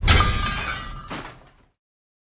Open_Forge.wav